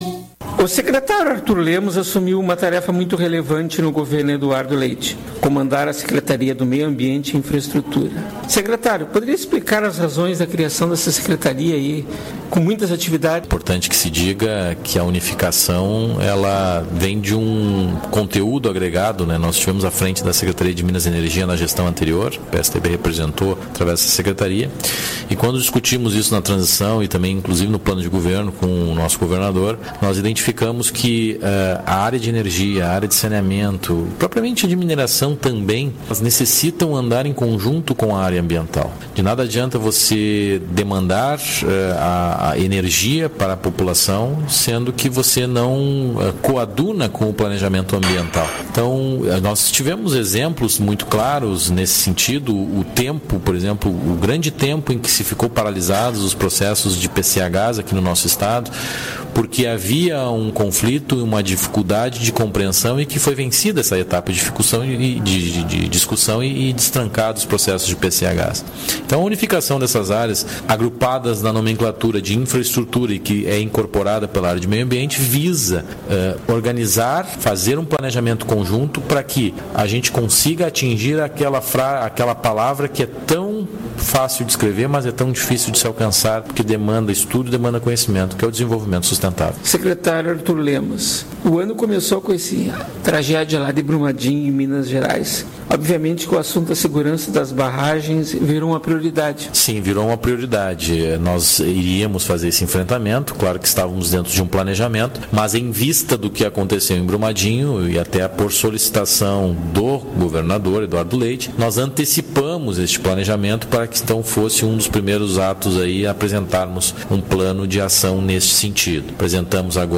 (transcri��o autom�tica, sujeita a erros) Sonora com Secret�rio de Meio Ambiente e Infraestrutura, Arthur Lemos